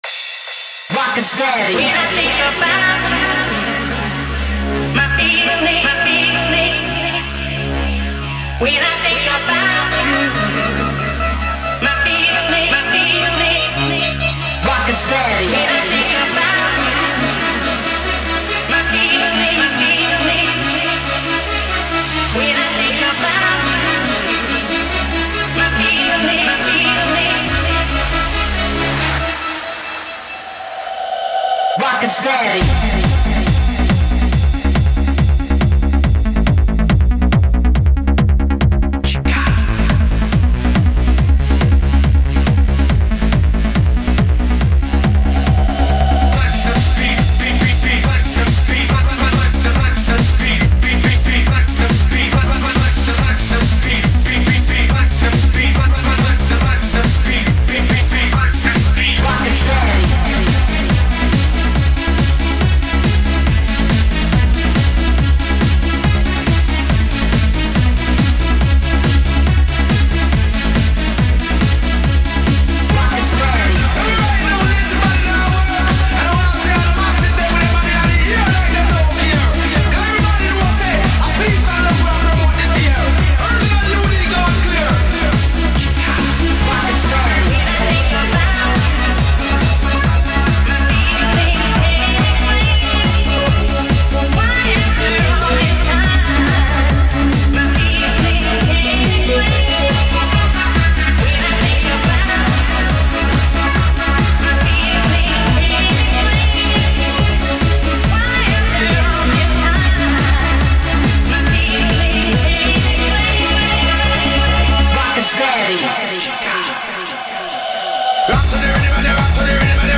(Ремикс)
Для ценителей "EURODANCE" Сэмплы: Свои+вокал...